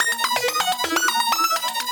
SaS_Arp03_125-E.wav